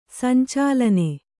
♪ sancālane